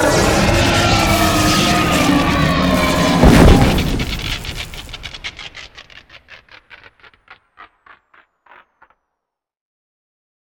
bigdie1.ogg